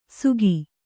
♪ sugi